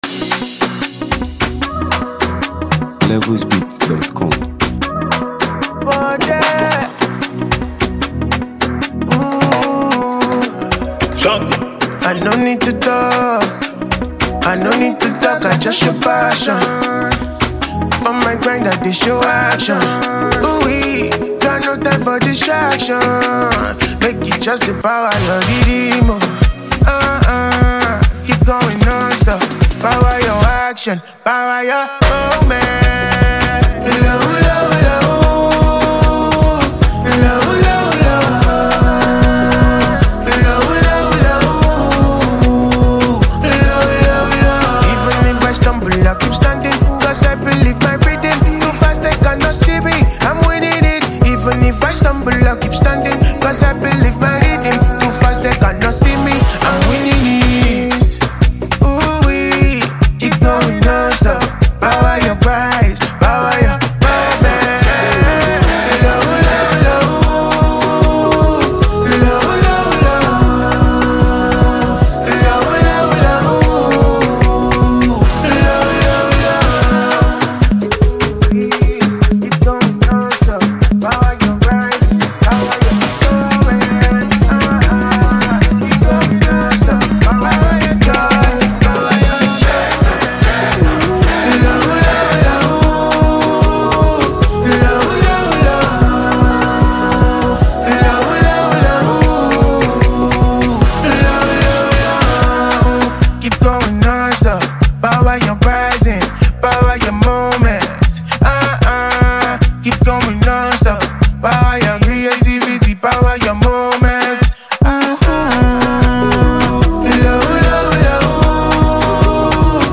Nigeria Music 2025 2:40